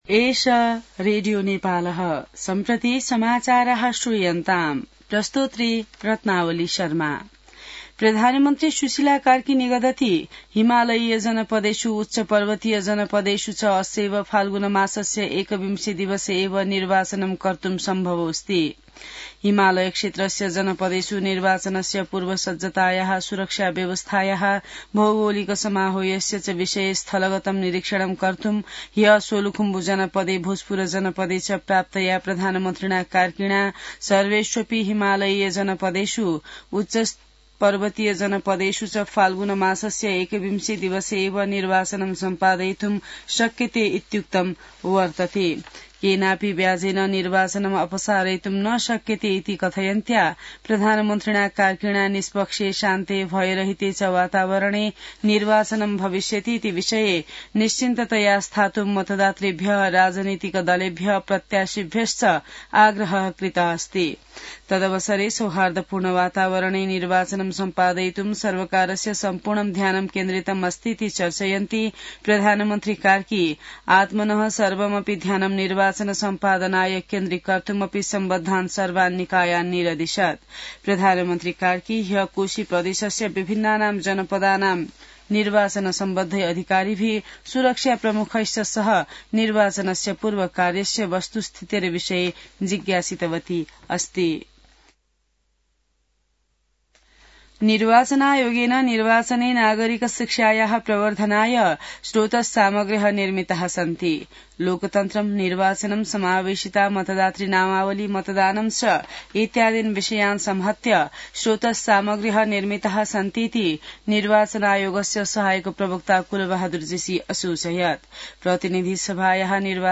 An online outlet of Nepal's national radio broadcaster
संस्कृत समाचार : १ फागुन , २०८२